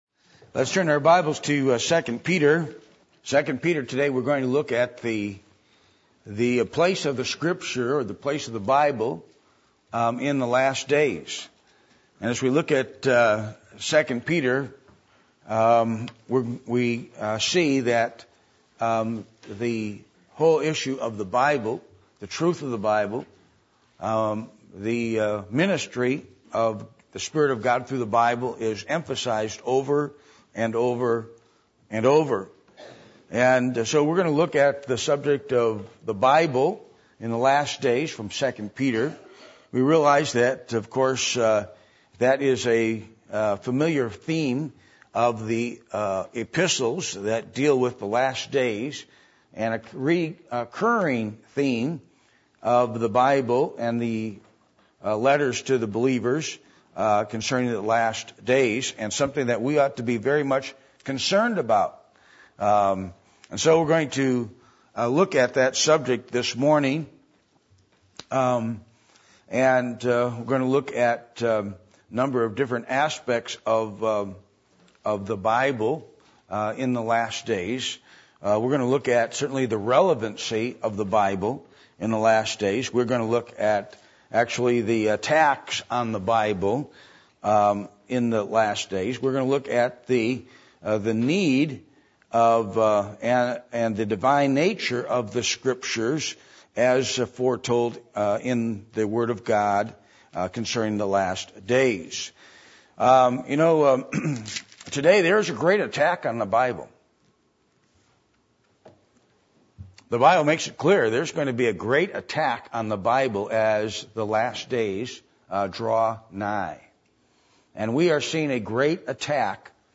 Passage: 2 Peter 1:1-22 Service Type: Sunday Morning